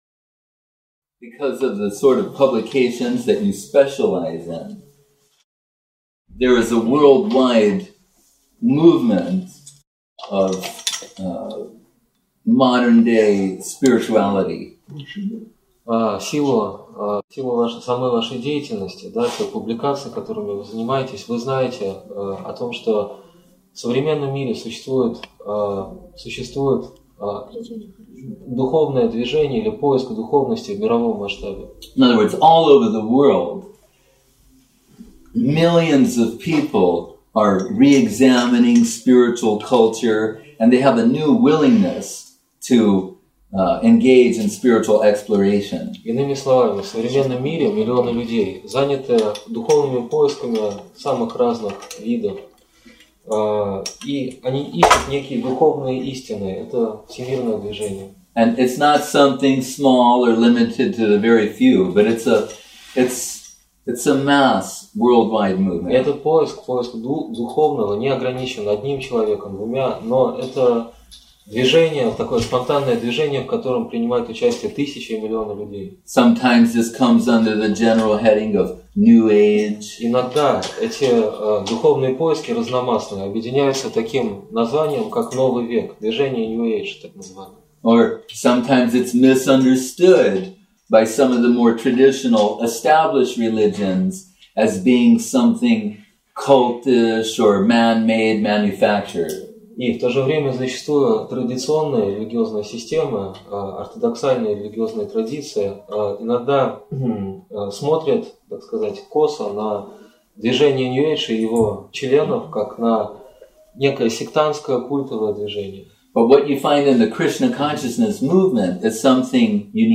Встреча с журналистами Представление о сознании Кришны